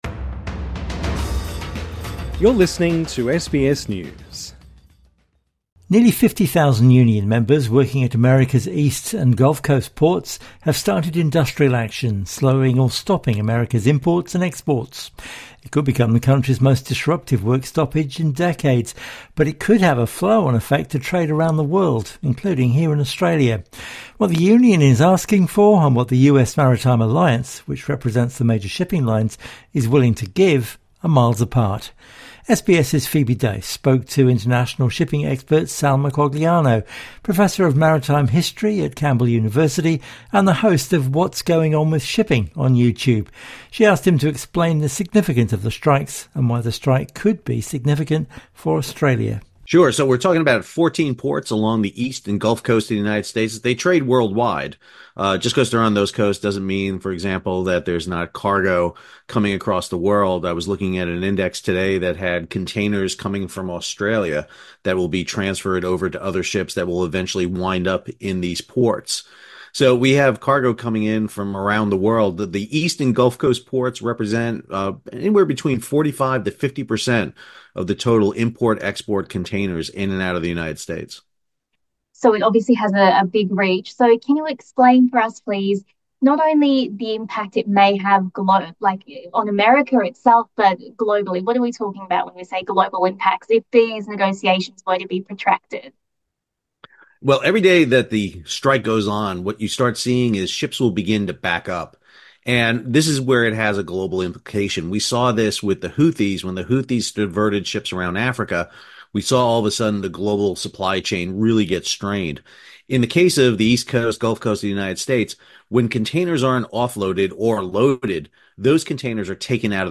INTERVIEW: Why a strike at US ports could hit trade here in Australia